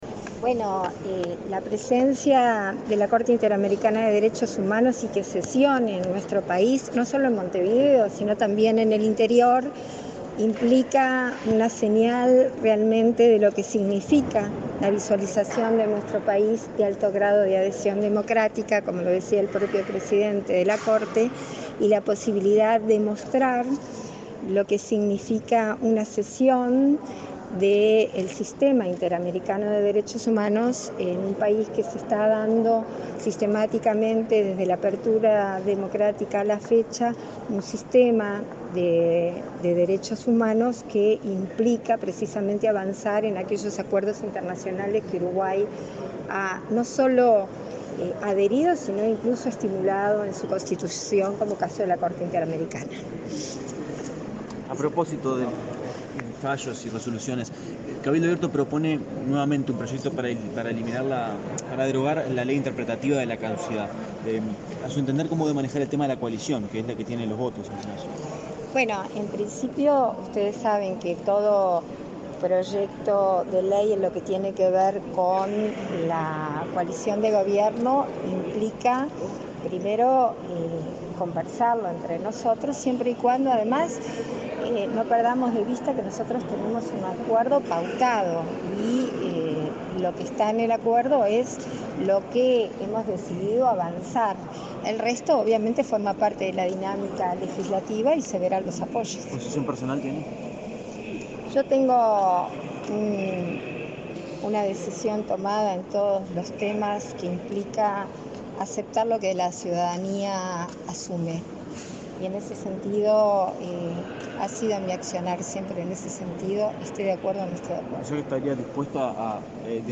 Declaraciones de la vicepresidenta de la República, Beatriz Argimón
Declaraciones de la vicepresidenta de la República, Beatriz Argimón 11/10/2022 Compartir Facebook X Copiar enlace WhatsApp LinkedIn La vicepresidenta de la República, Beatriz Argimón, y el canciller, Francisco Bustillo, participaron en la ceremonia de instalación en Uruguay del 153.° Período Ordinario de Sesiones de la Corte Interamericana de Derechos Humanos. Luego Argimón dialogó con la prensa.